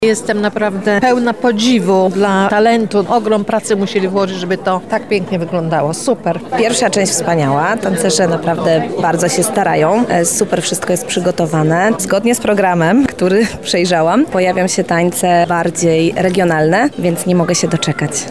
goście